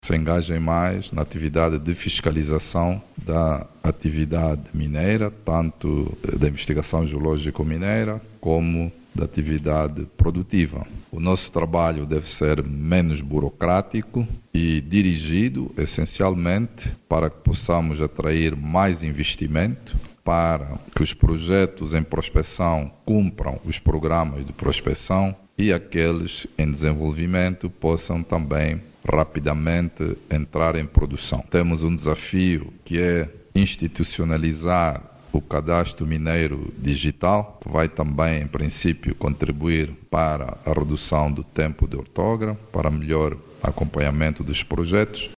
A Agência Nacional dos Recursos Minerais foi desafiada a apostar na atracção de investidores nacionais e estrangeiros para o nosso país. O repto foi lançado pelo Ministro dos Recursos Minerais, Petróleo e Gás, Diamantino Azevedo, quando conferia posse aos membros do conselho daquela agência.